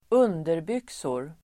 Uttal: [²'un:derbyk:sor]